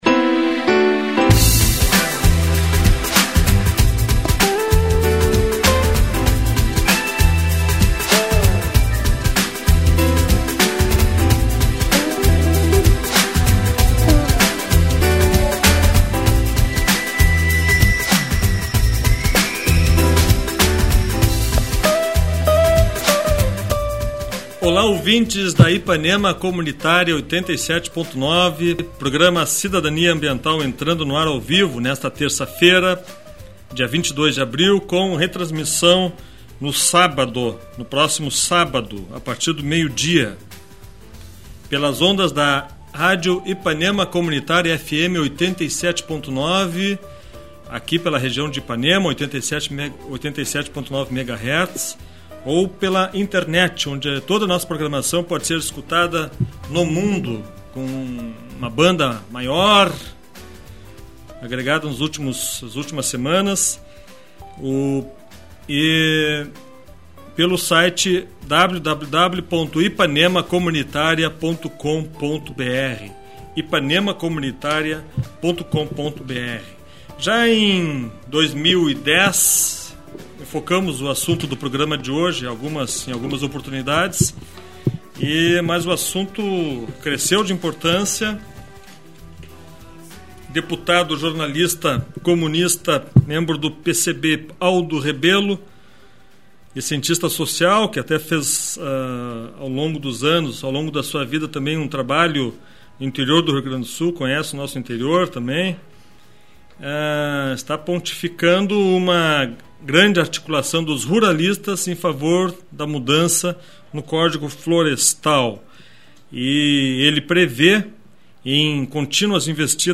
A Rádio Ipanema Comunitária 87,9 FM situa-se no bairro de Ipanema e transmite para a região sul de Porto Alegre.
Na pauta, normalmente uma entrevista.